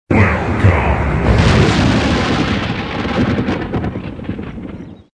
Kermis Jingle's  2013
Welcome/thunder
Jingle-05-Welkome thunder.mp3